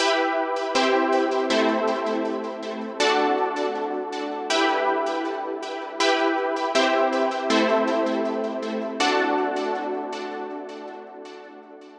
Tag: 80 bpm Chill Out Loops Synth Loops 2.02 MB wav Key : E